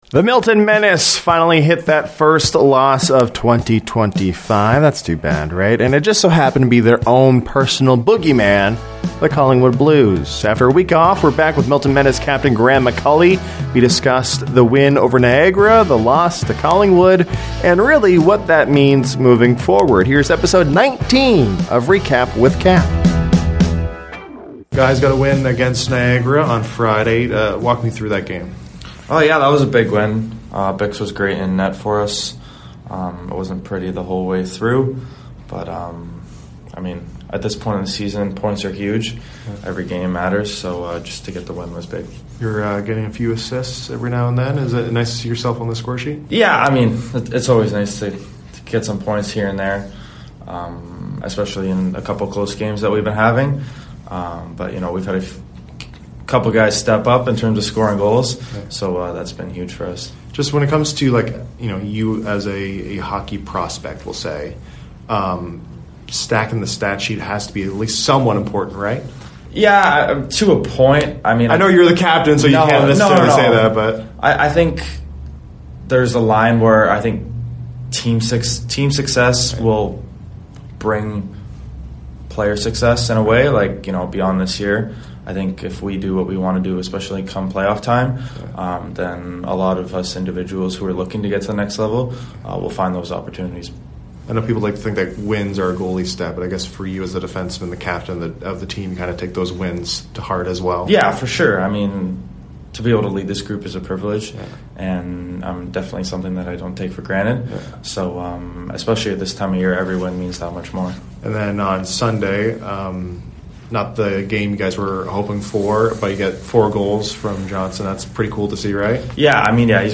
We post ‘Recap with Cap’ episodes on a near weekly basis, as well as daily newscasts to keep you up-to-date on what’s happening in and around Milton.